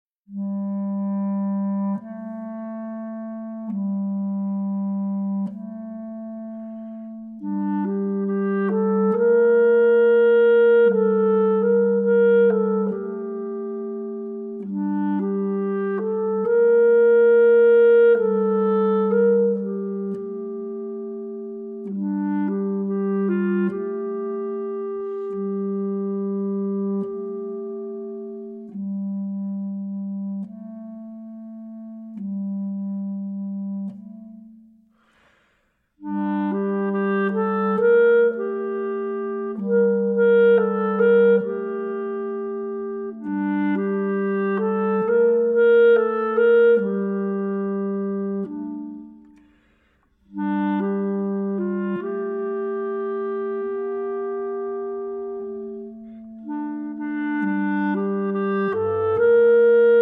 Clarinet
Bass Clarinet